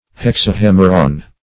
hexahemeron - definition of hexahemeron - synonyms, pronunciation, spelling from Free Dictionary
Search Result for " hexahemeron" : The Collaborative International Dictionary of English v.0.48: Hexahemeron \Hex`a*hem"er*on\, n. [NL., fr. Gr.